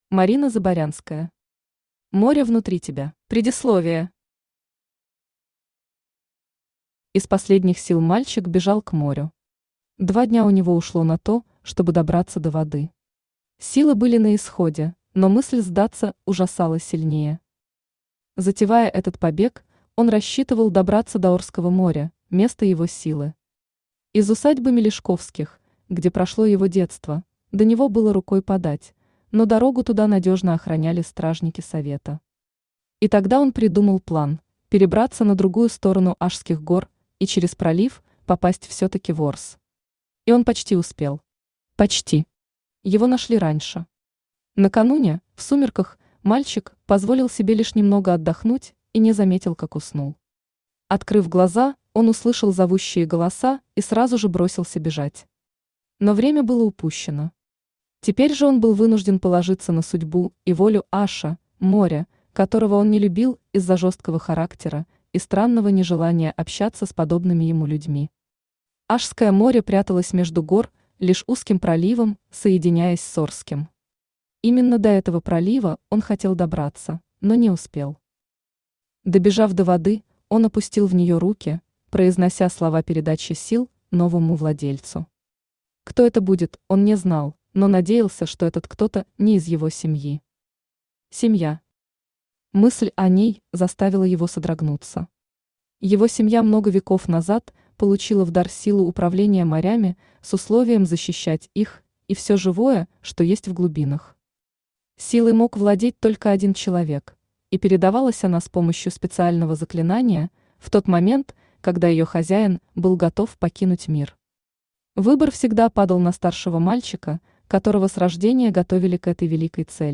Аудиокнига Море внутри тебя | Библиотека аудиокниг
Aудиокнига Море внутри тебя Автор Марина Заборянская Читает аудиокнигу Авточтец ЛитРес.